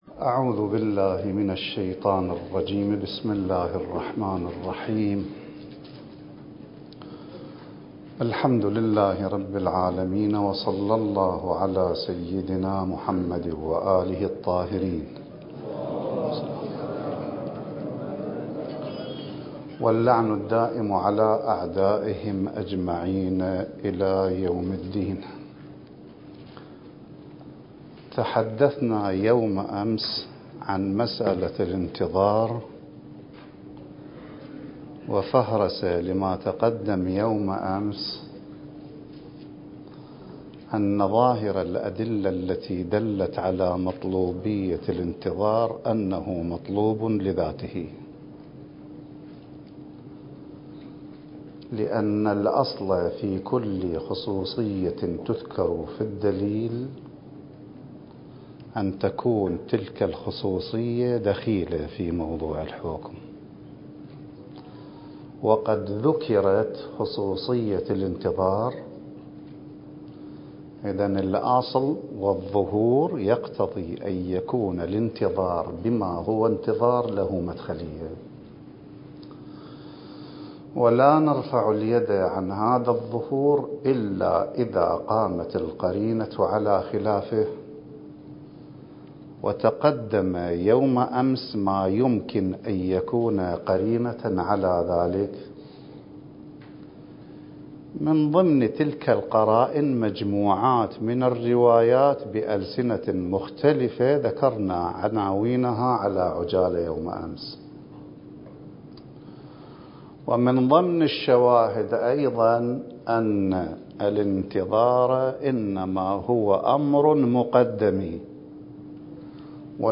الدورة المهدوية الأولى المكثفة (المحاضرة الثانية) التي أقامها معهد تراث الأنبياء (عليهم السلام) للدراسات الحوزوية الألكترونية التابع للعتبة العباسية المقدسة وبالتعاون مع مركز الدراسات التخصصية في الإمام المهدي (عجّل الله فرجه) ومدرسة دار العلم للإمام الخوئي (قدس سره) المكان: النجف الأشرف التاريخ:2024